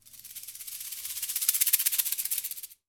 STR SHAKE.wav